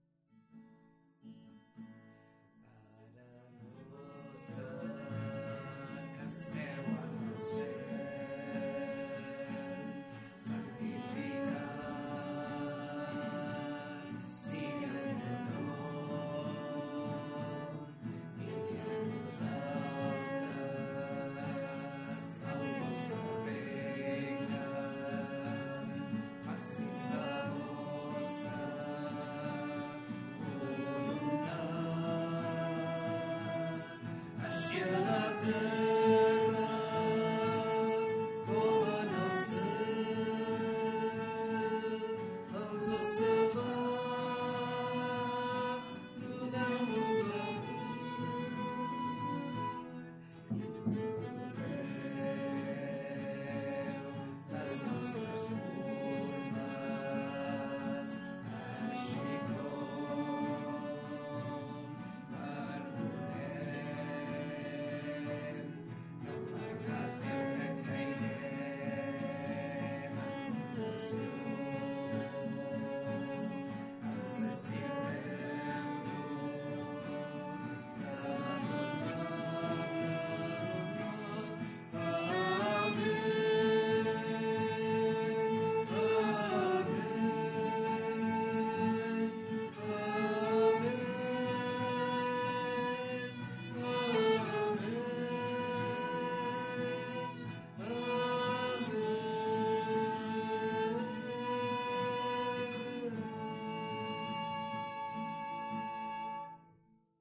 Pregària de Taizé a Mataró... des de febrer de 2001
Capella dels Salesians - Diumenge 27 d'abril de 2025